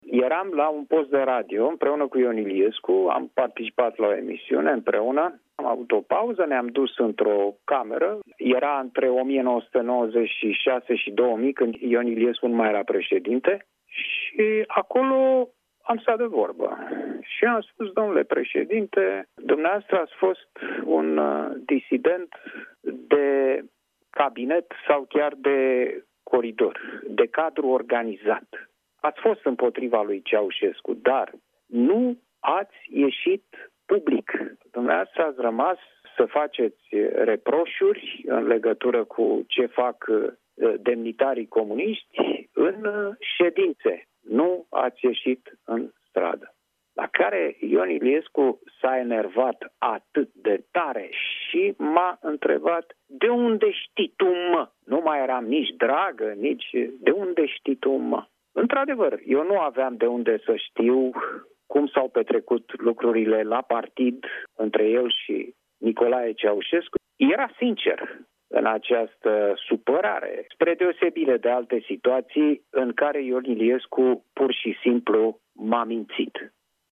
Gazetarul și scriitorul Cristian Tudor Popescu a povestit la Europa FM o amintire legată de fostul președinte, Ion Iliescu. Întâmplarea a avut loc în perioada 1996 – 2000, când Ion Iliescu nu mai era președintele României. Cei doi participaseră împreună la o emisiune de radio.